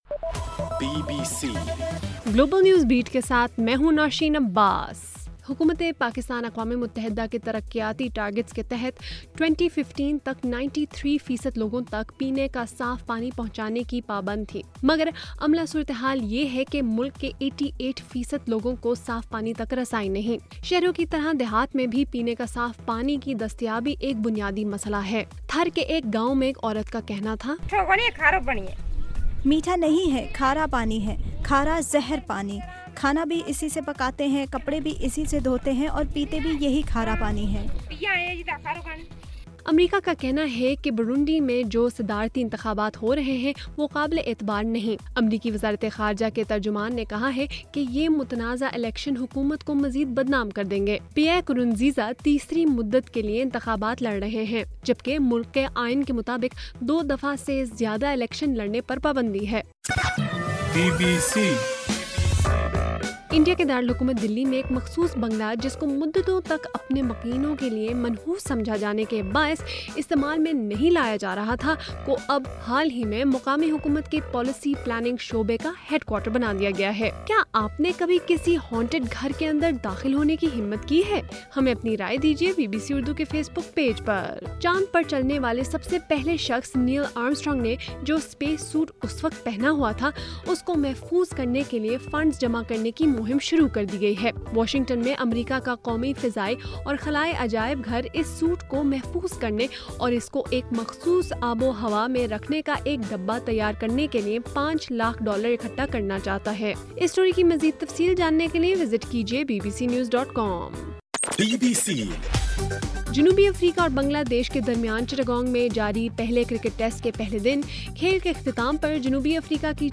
جولائی 21: رات 8 بجے کا گلوبل نیوز بیٹ بُلیٹن